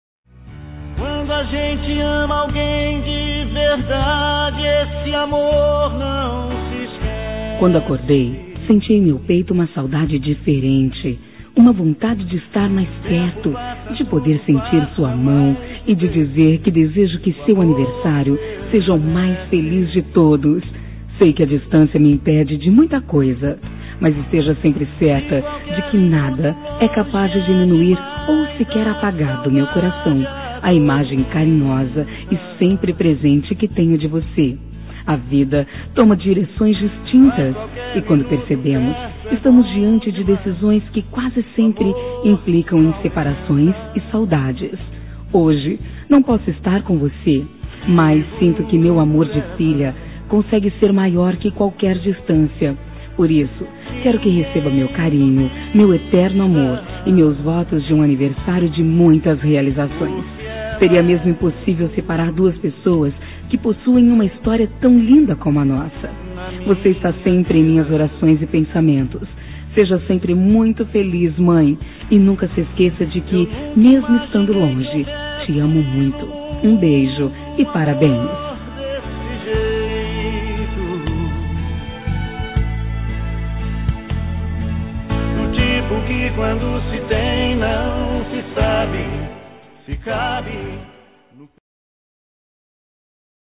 Telemensagem Aniversário de Mãe – Voz Feminina – Cód: 1420 – Distante